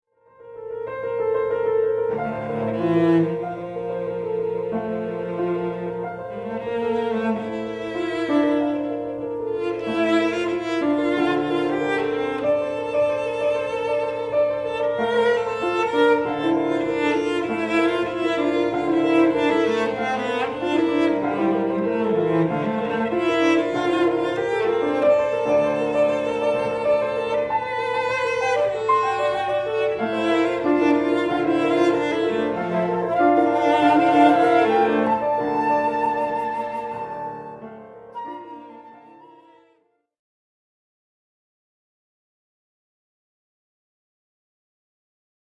フルート、チェロ、ピアノ(Flute, Cello, Piano)